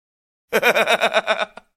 50 Cent - Laugh 02